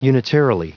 Prononciation du mot unitarily en anglais (fichier audio)
Prononciation du mot : unitarily